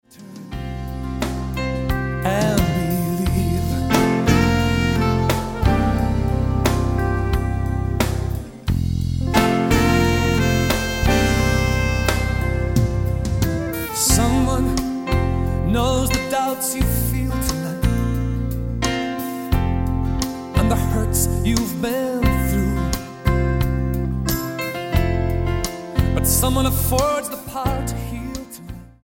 STYLE: Pop
acoustic guitar